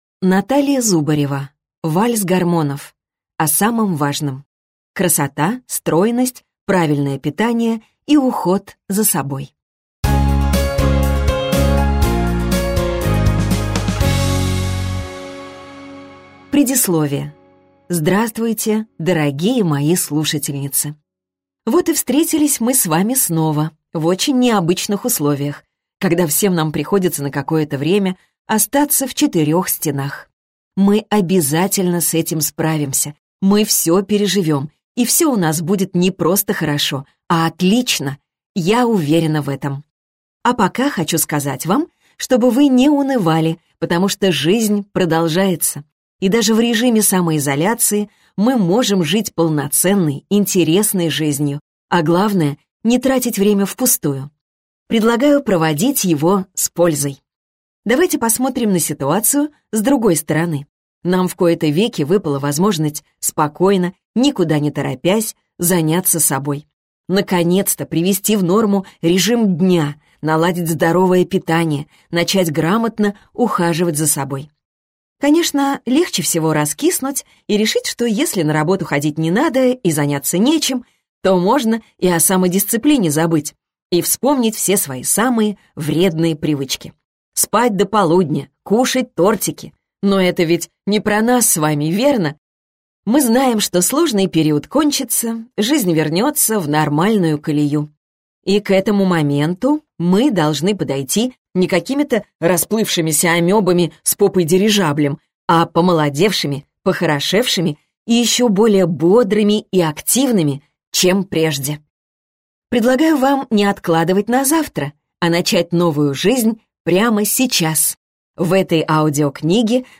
Аудиокнига Вальс гормонов. О самом важном. Красота, стройность, правильное питание и уход за собой | Библиотека аудиокниг